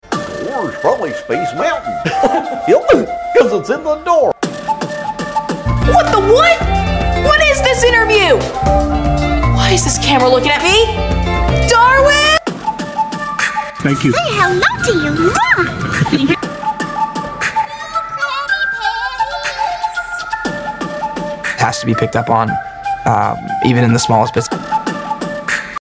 Cartoon Voice Mp3 Sound Effect
Cartoon Voice Actor part 3